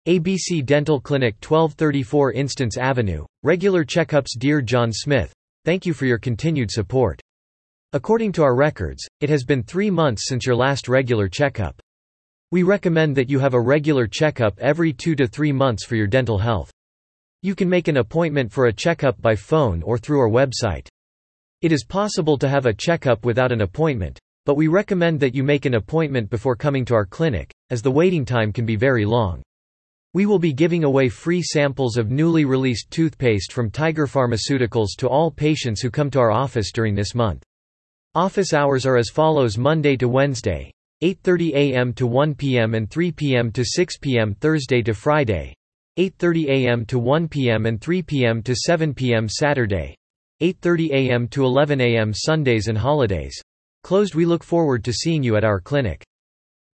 本文読み上げ